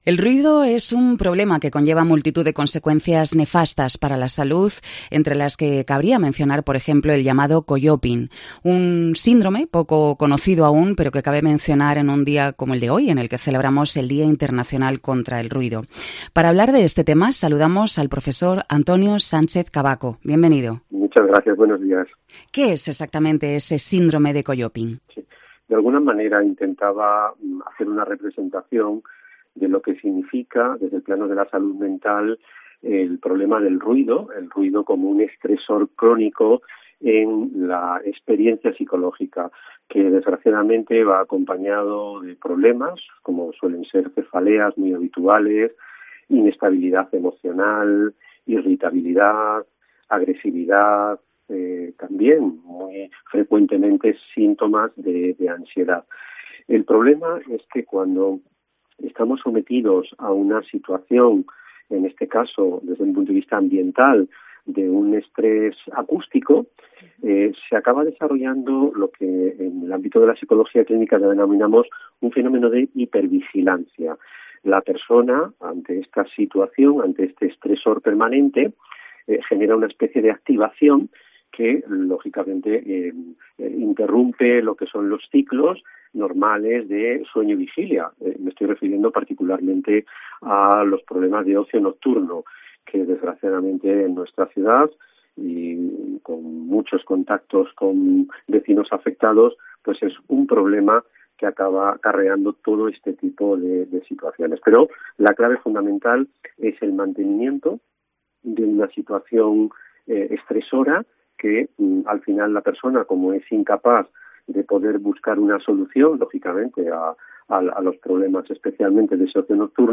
COPE Salamanca entrevista